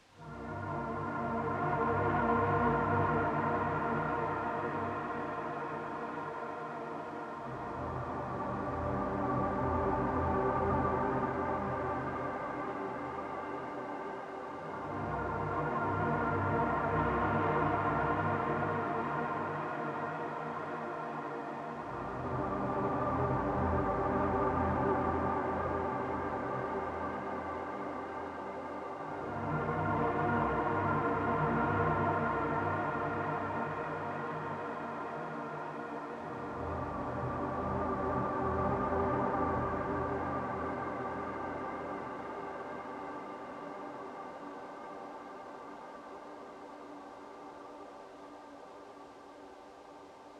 I was making some fun granular drones tonight in Pigment’s granular engine with Pigment’s own effects.
Sure! Here is what I came up with, just modulating the sample start time and the volume of the sample a bit.